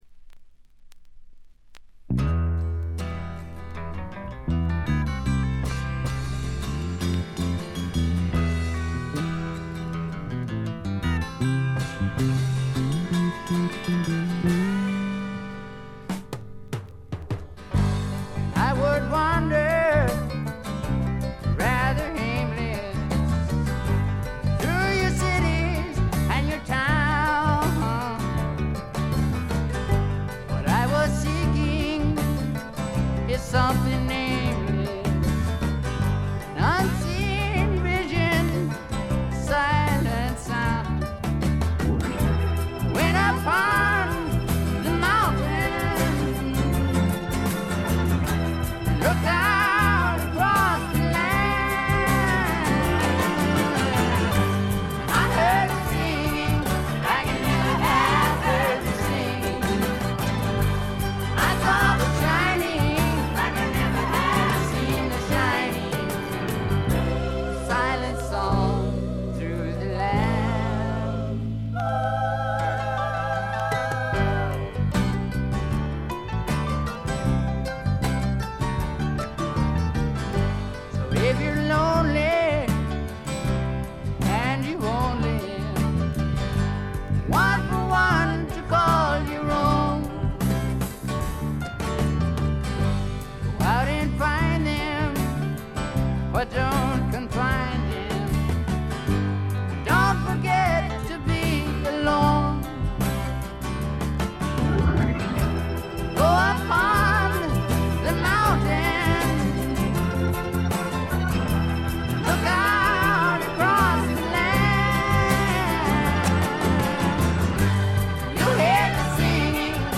ホーム > レコード：米国 SSW / フォーク
チリプチ、散発的なプツ音が多め大きめ。
全編通してびしっと決まったシンガー・ソングライター／スワンプロックの理想郷。
試聴曲は現品からの取り込み音源です。